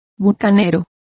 Complete with pronunciation of the translation of buccaneer.